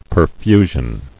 [per·fu·sion]